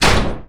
IMPACT_Generic_02_mono.wav